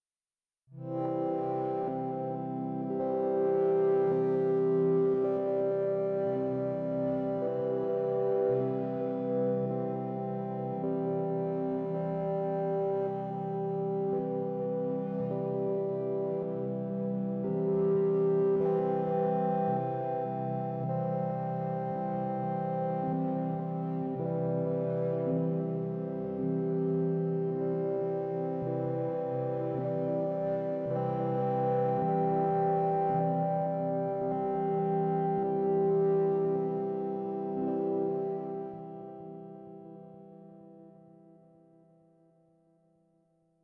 Novation Peak:
The filter is so musical sounding, just throwing a S+H LFO on it with high resonance brings out these beautiful overtones: